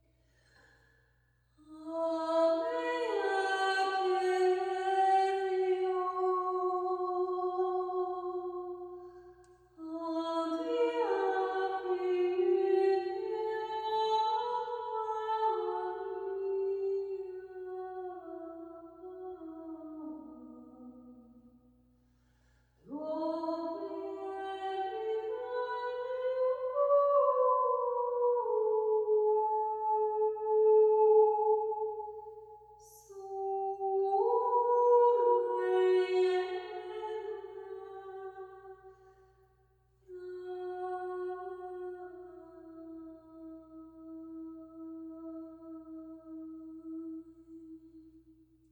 A Capella                    Durée 05:40